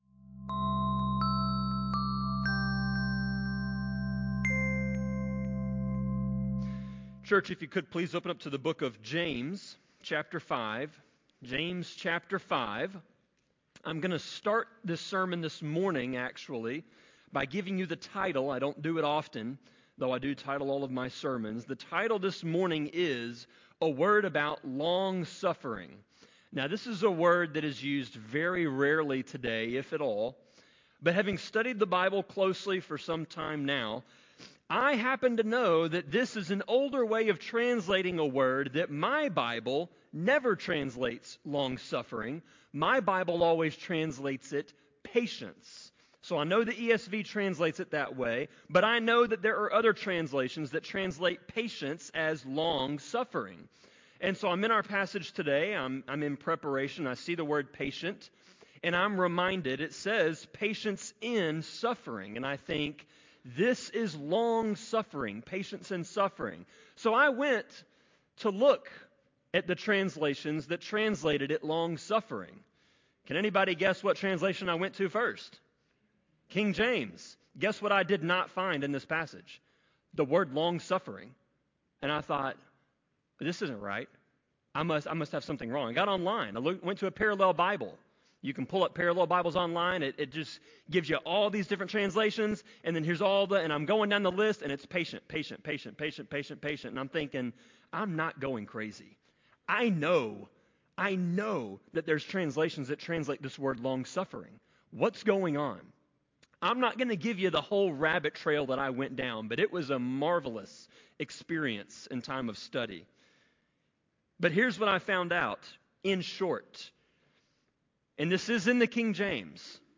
Sermon-25.8.10-CD.mp3